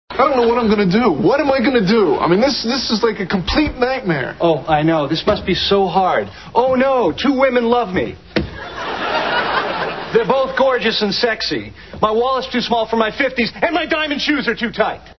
These are .mp3 soundbites from the NBC television show "Friends."